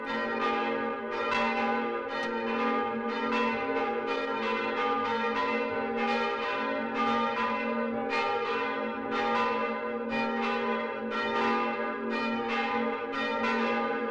Sie stehen auf dem Ergenstein und hören in Ihrer Vorstellung die vereinigten Hahnstätter und Schönborner Glocken.
Wenn Sie auf das Lautsprechersymbol klicken, hören Sie das Geläut beider Kirchen.